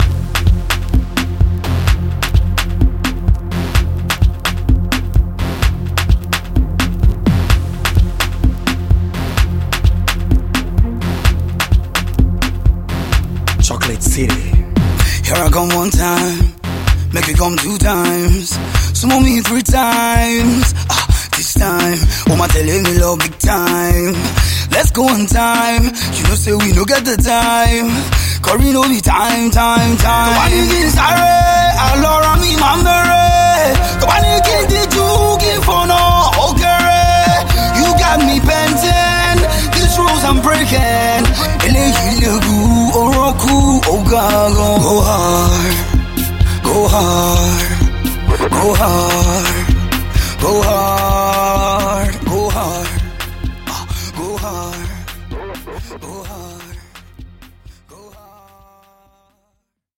Afro-Dance meets Afro-Pop joint